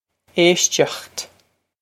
Éisteacht Ay-sht-ockht
Pronunciation for how to say
This is an approximate phonetic pronunciation of the phrase.